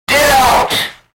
Get Out Sound Effect
Aggressive male voice shouting ‘Get out!’ with harsh, clipped distortion and intense vocal grit.
Genres: Sound Effects
Get-out-sound-effect.mp3